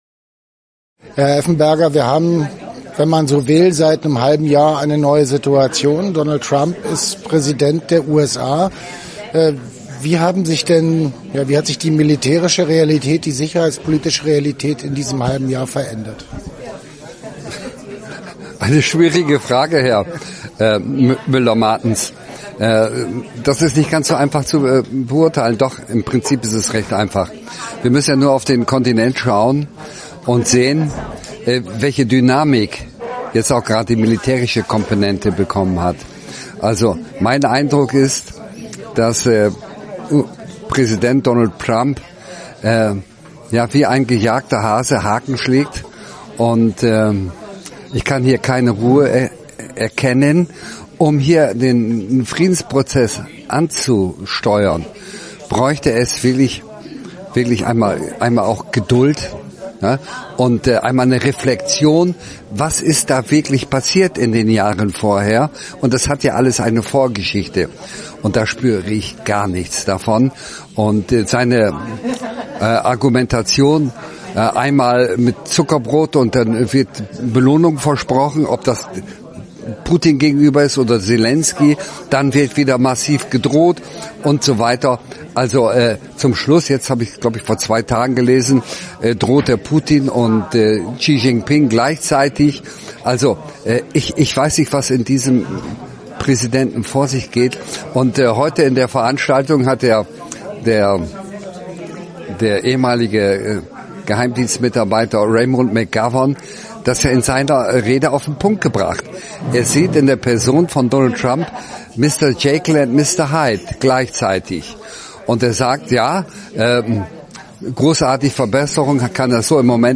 Interview des Tages